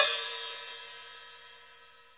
CM HAT OP 26.wav